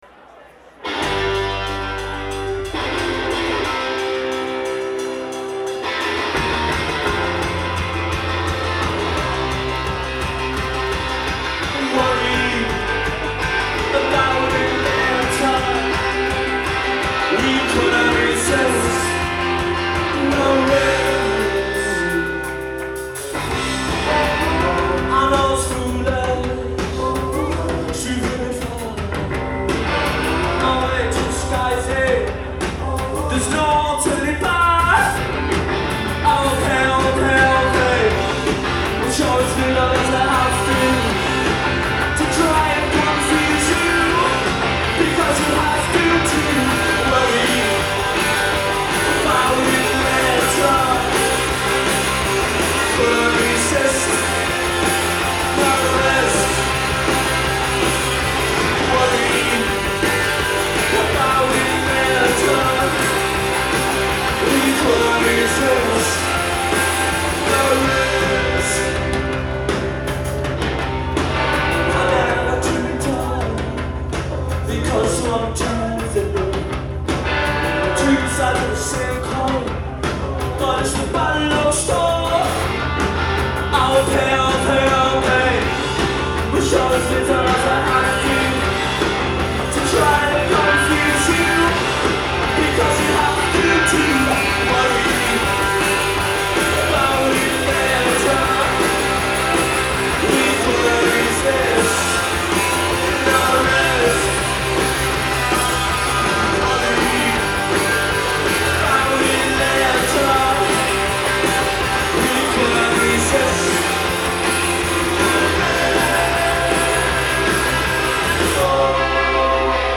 Live at The Paradise